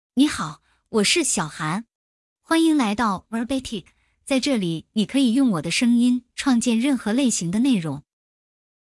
FemaleChinese (Mandarin, Simplified)
XiaohanFemale Chinese AI voice
Voice sample
Listen to Xiaohan's female Chinese voice.
Xiaohan delivers clear pronunciation with authentic Mandarin, Simplified Chinese intonation, making your content sound professionally produced.